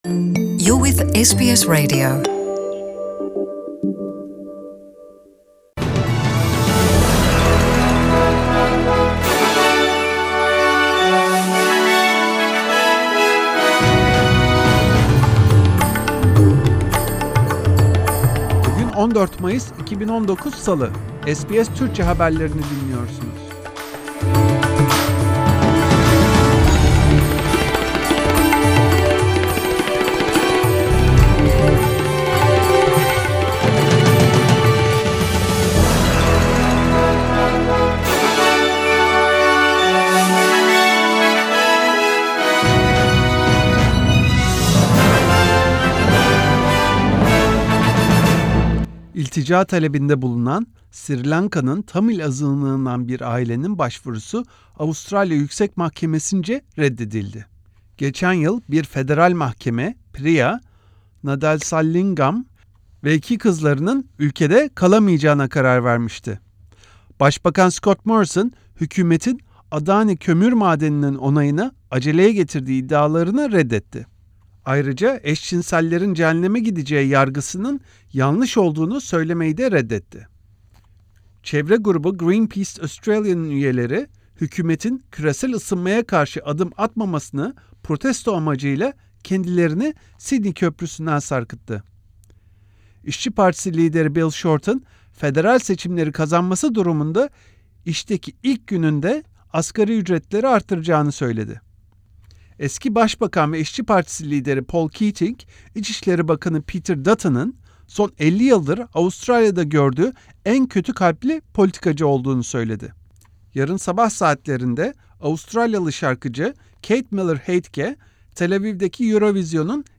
SBS Türkçe'den günün haberleri. Başbakan Scott Morrison, eşcinsellerin cehenneme gitmeyeceğini söyleyemedi. İşçi Partisi lideri Bill Shorten, bu cumartesi günü düzenlenecek seçimlerde kazanırsa, işteki ilk gününde asgari ücreti artıracağını açıkladı. Yarın sabah 5'te Avustralyalı şarkıcı Kate-Miller Heidke, Tel Aviv'deki Eurovision 2019 elemelerinde yarışacak.